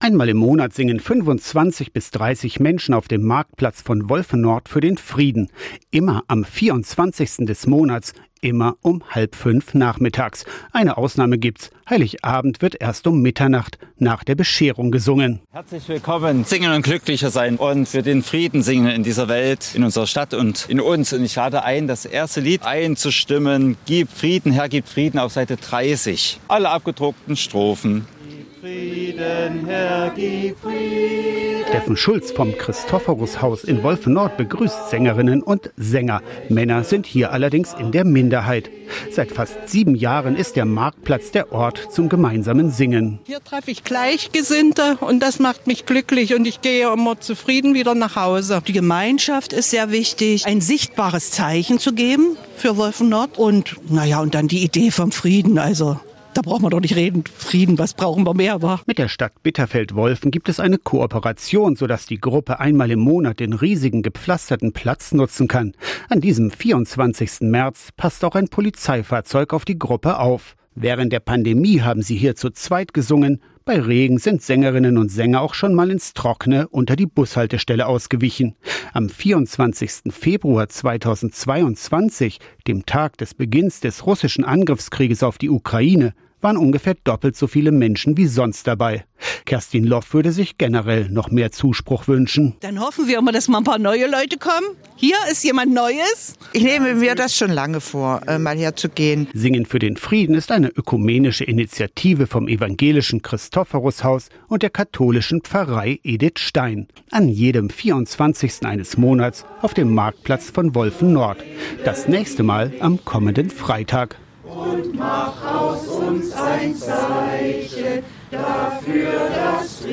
Einmal im Monat singen 25 bis 30 Menschen auf dem Marktplatz von Wolfen-Nord für den Frieden.